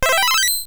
egg_hatch.wav